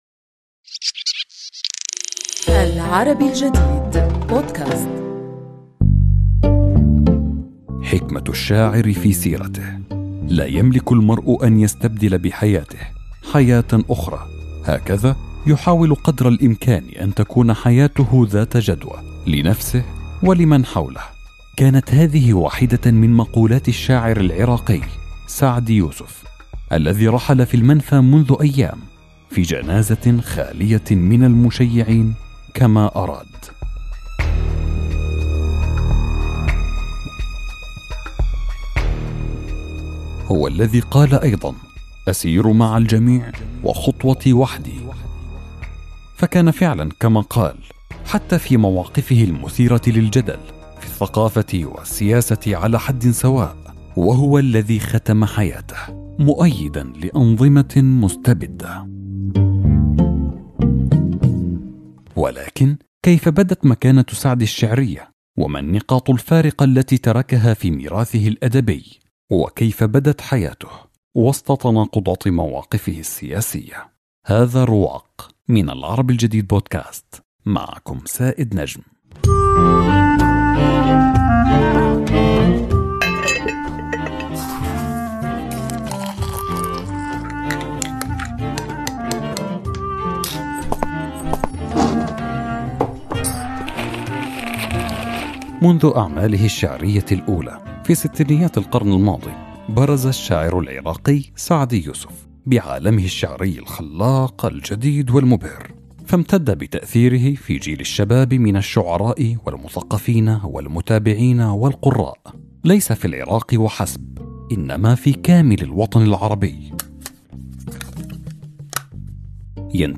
الأكاديمية والناقدة